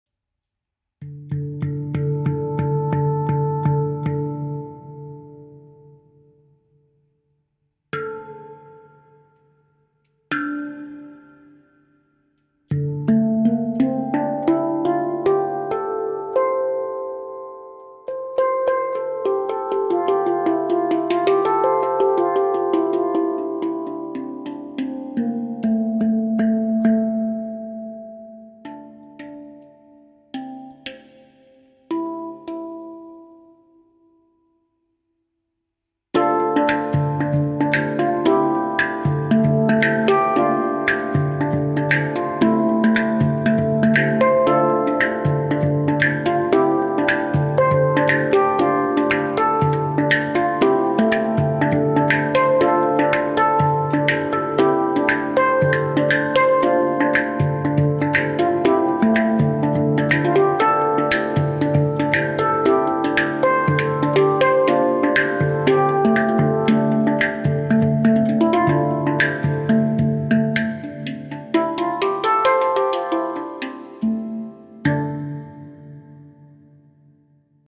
This Canopus 10 Notes handpan stands out for the richness of its notes when struck.
• 10 notes : D3/A3-Bb3-C4-D4-E4-F4-G4-A4-C5
• Scale : D Minor
• Material : STL 430 stainless steel
Complete and particularly resonant, this handpan will produce intense vibrations that will easily fill all the space available around it.
handpan-10-notes-canopus-do-minor-1.mp3